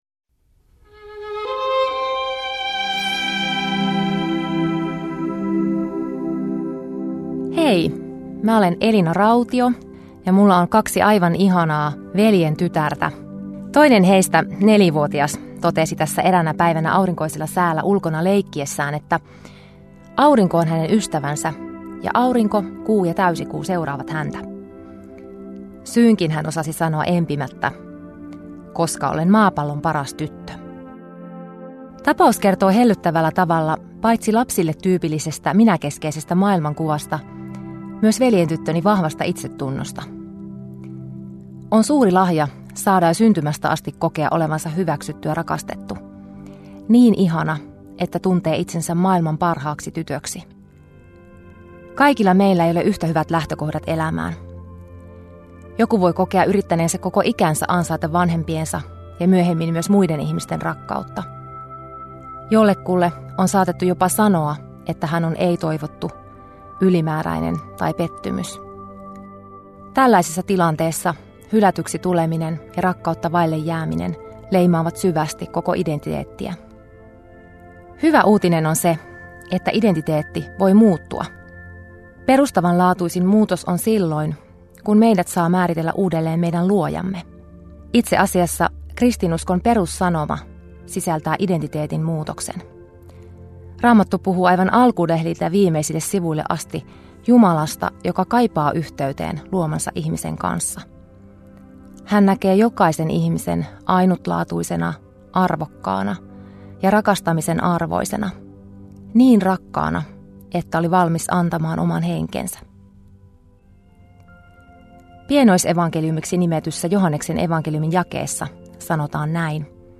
Radio Dei lähettää FM-taajuuksillaan radiohartauden joka arkiaamu kello 7.50. Hartaus kuullaan uusintana iltapäivällä kello 17.05.